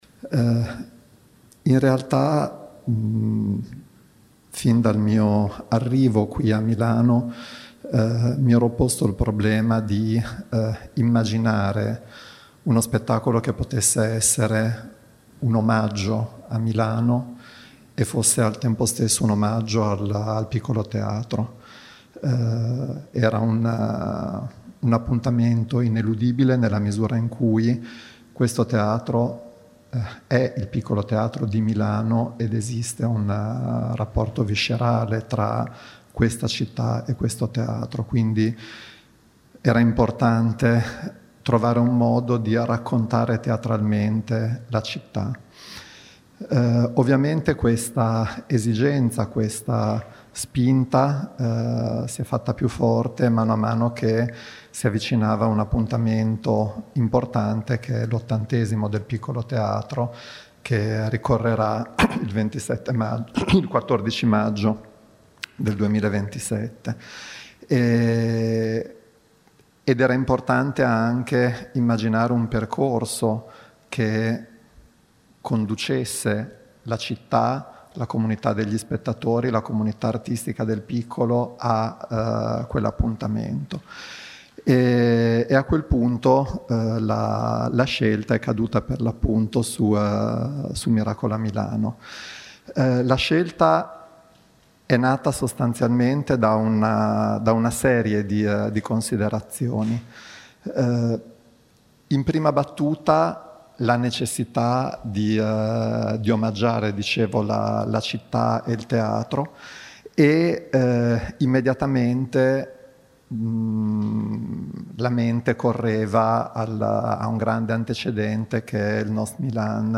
Sentiamo il regista Claudio Longhi.